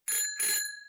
Ring Bell.wav